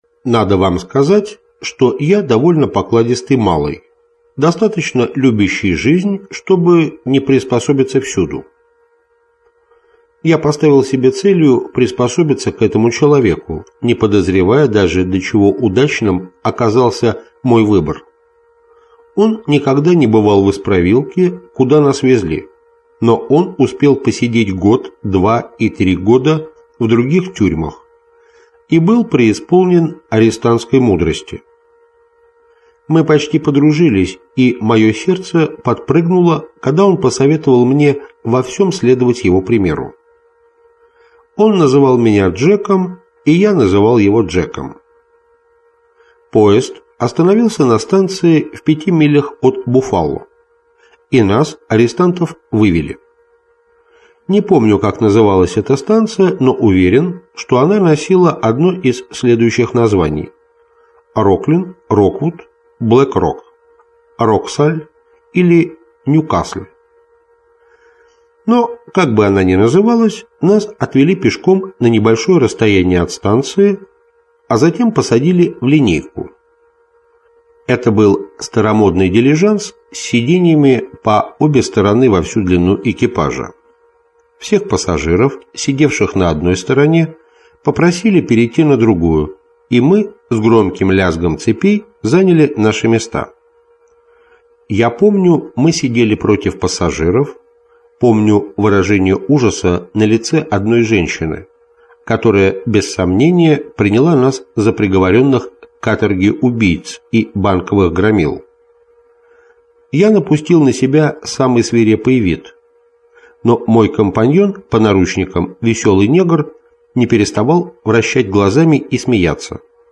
Аудиокнига Лунный лик. Сила сильных. Дорога | Библиотека аудиокниг